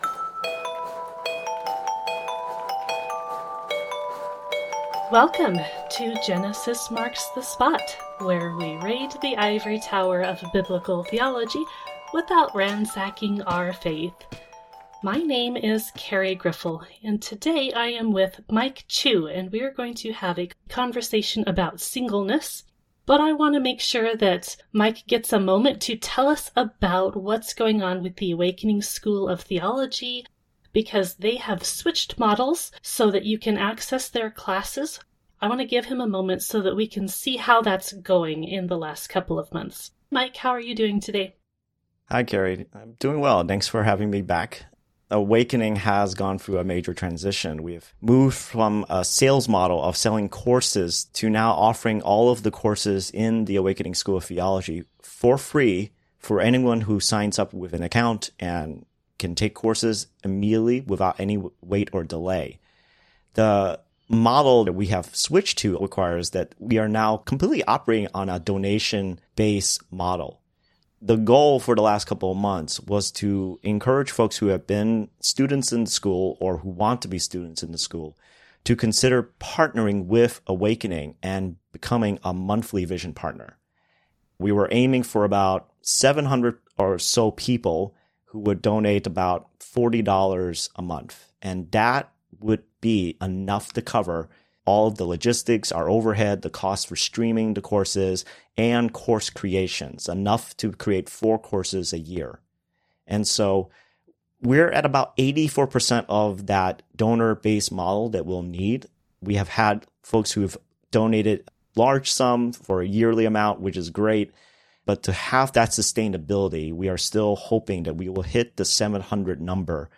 A fantastic conversation that opens the doors to listening to one another and seeing how we reflect Jesus in our midst.